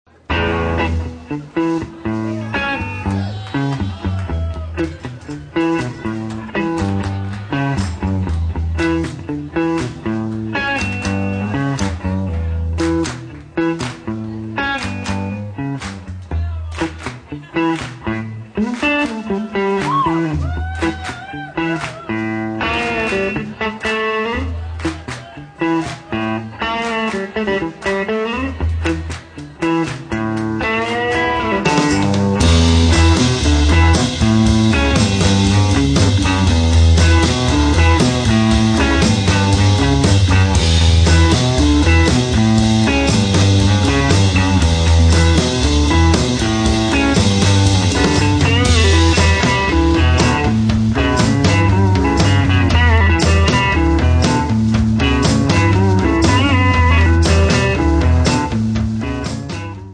trombone
chitarra
Hammond b3 organ
batteria